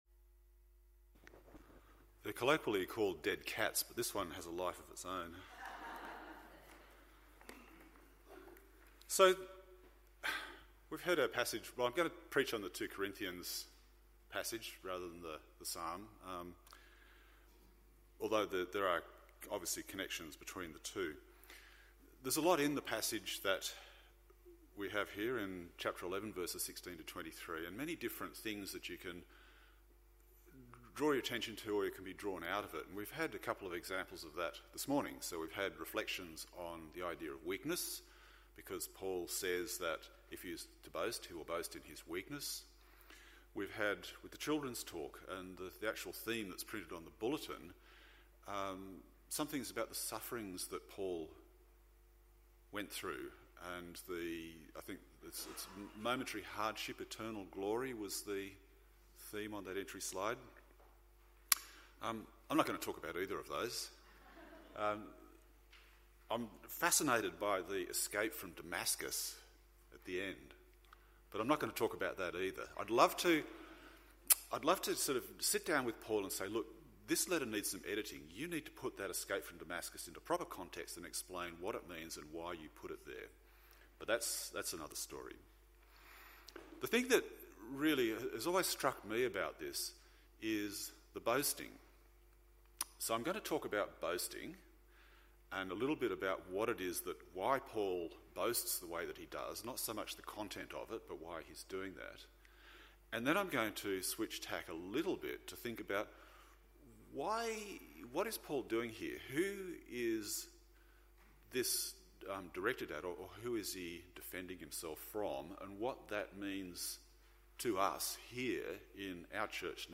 Eternal Glory Passage: 2 Corinthians 11:16-33 Service Type: AM Service « Why Tolerate My Foolishness?